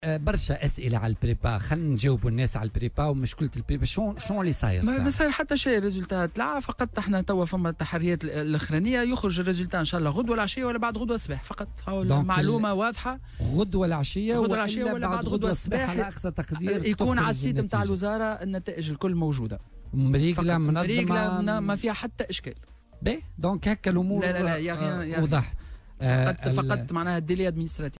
وأعلن ضيف بوليتكا، اليوم الثلاثاء، في رده على تساؤلات الطلبة، أن موعد نشر النتائج سيكون يوم غد الأربعاء 11 جويلية مساءً، أو صباح يوم الخميس 12 جويلية على أقصى تقدير، على أن تنشر على الموقع الرسمي للوزارة.